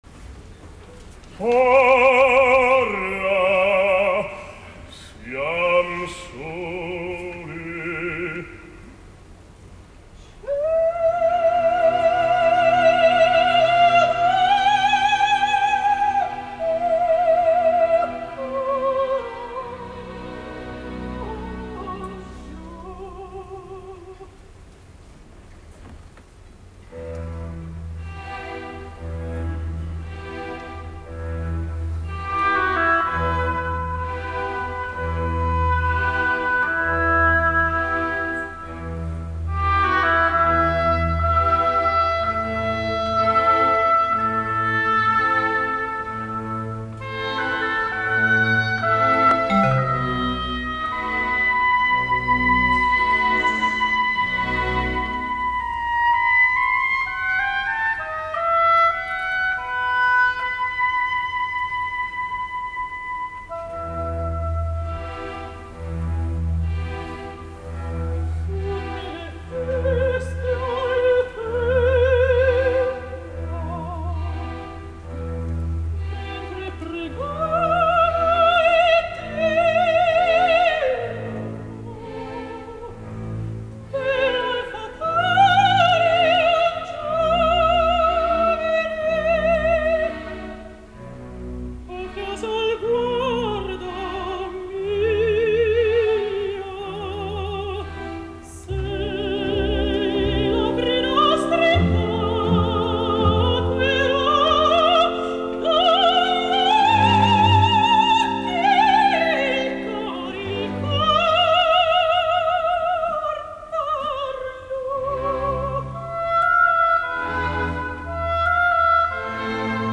La soprano moldava